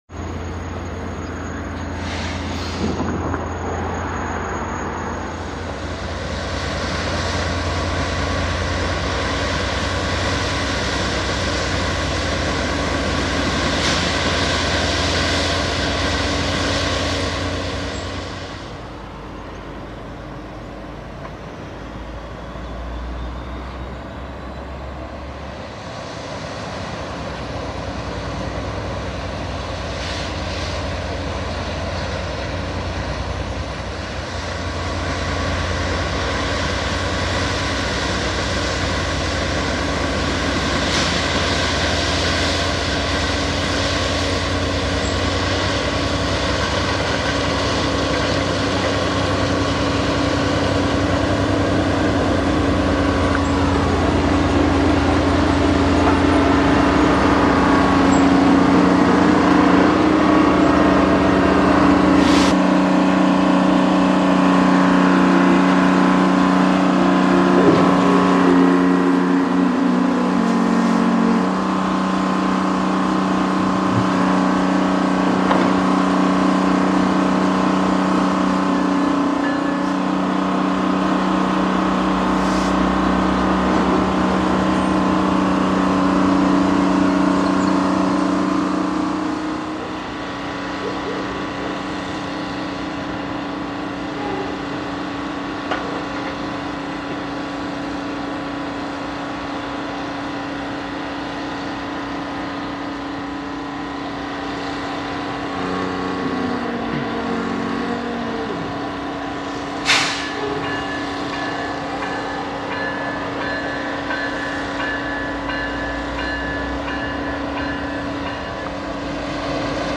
Train Plowing Dirt and Grading Work - Rare Track work equipment along the Withrow Sub